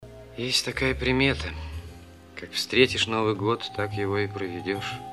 из фильмов
голосовые
советское кино